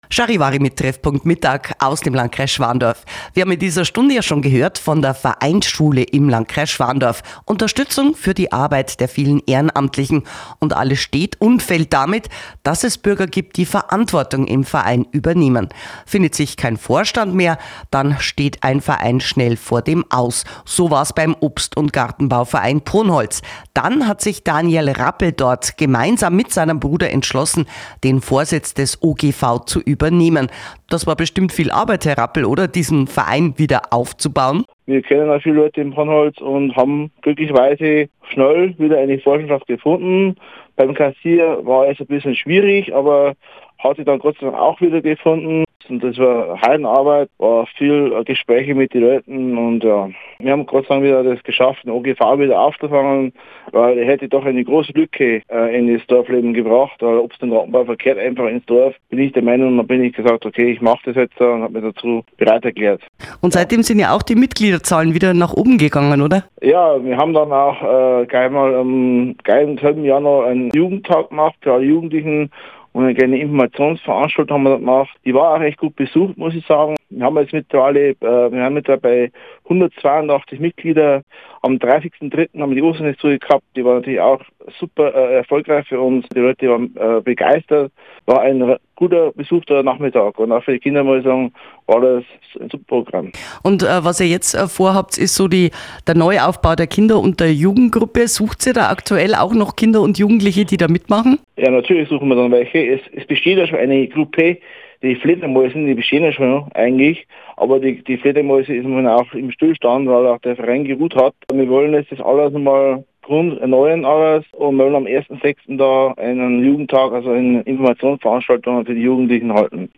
Interview mit Charivari